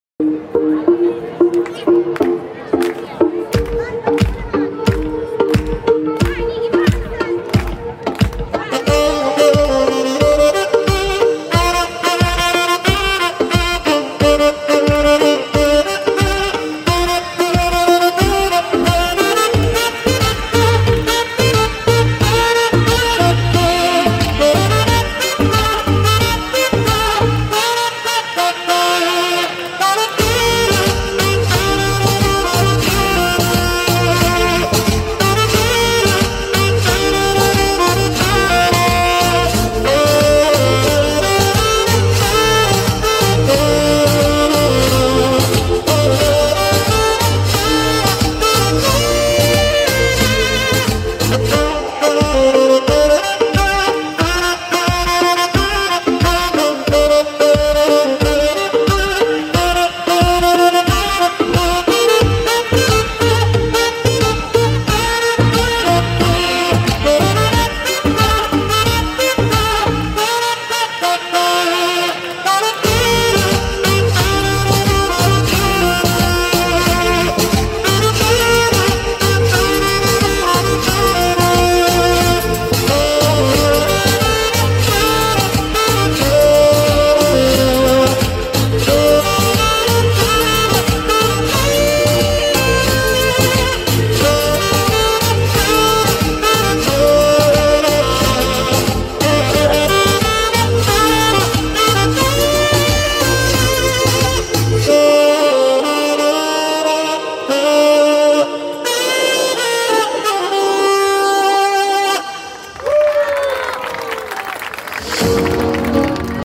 Saxophone Cover